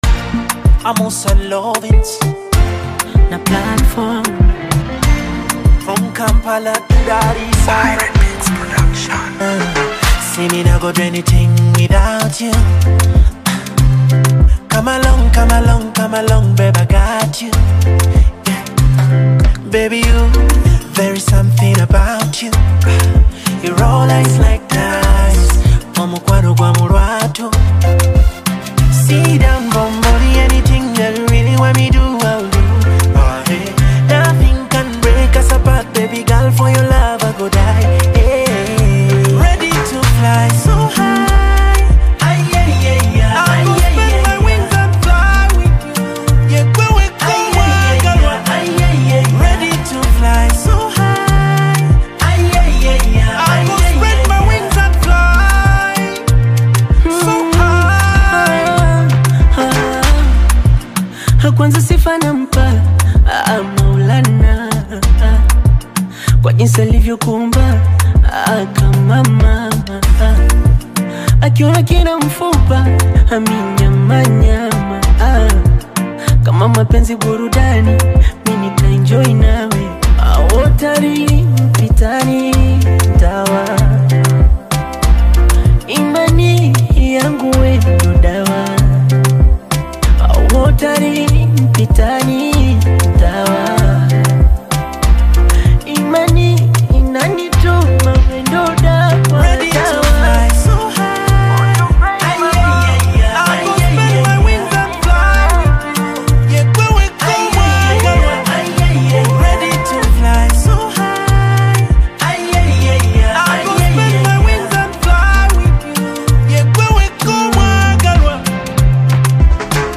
heartfelt and uplifting track
With emotional lyrics and smooth vocals